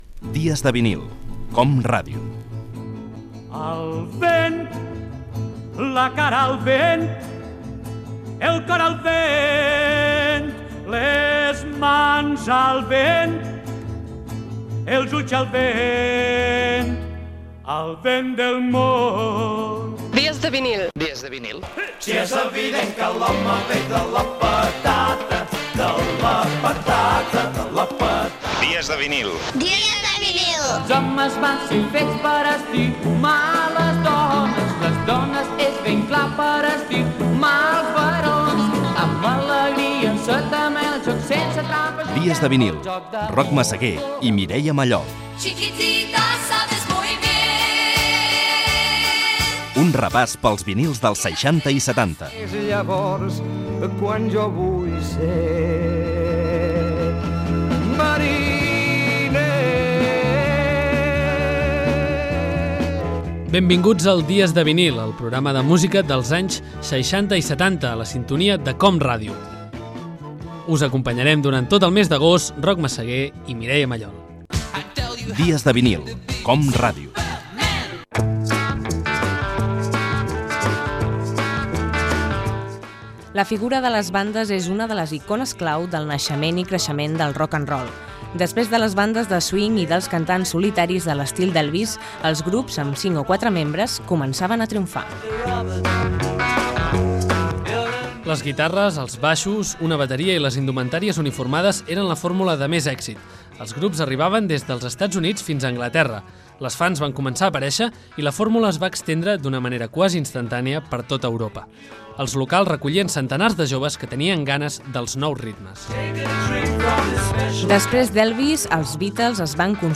Indicatiu del programa, presentació del programa, les bandes musicals dels anys 1960 i tema musical
Musical